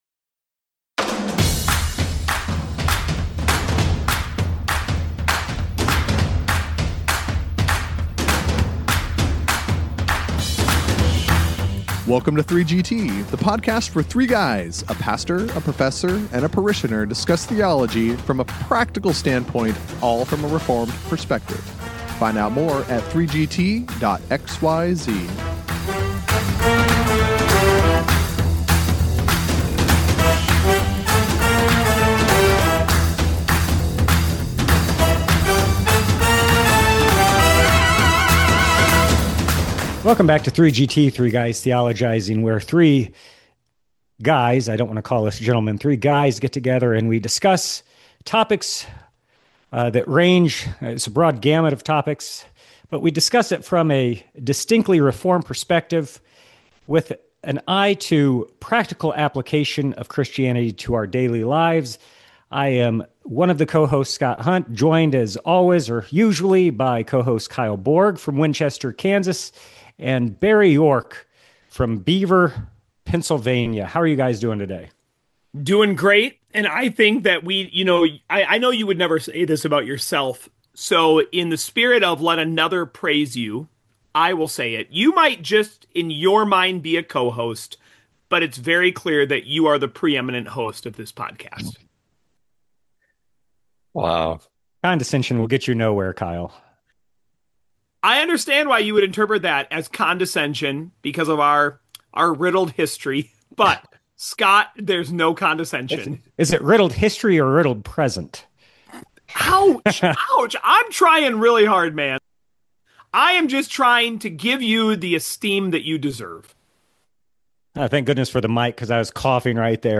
After their typical banter and razzing of one another, the guys become a bit more serious.